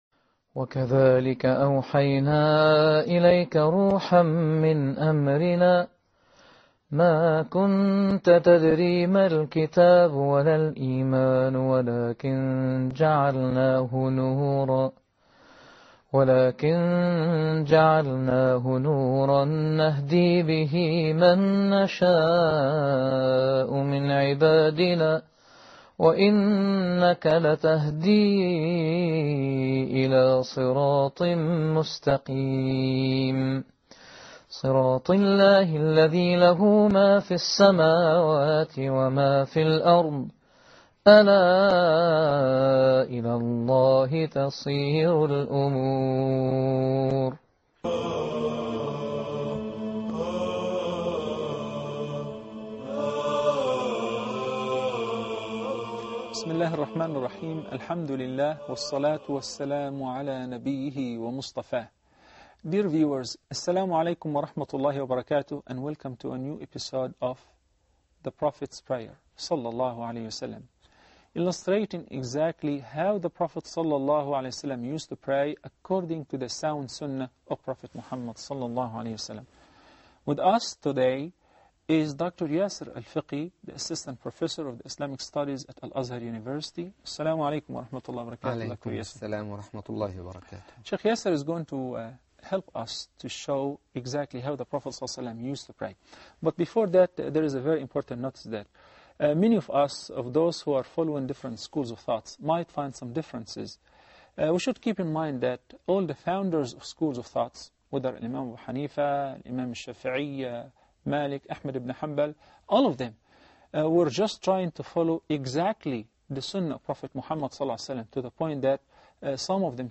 This first lesson in the series on the Prophet’s prayer establishes the foundational principles that every Muslim must understand before they can pray correctly.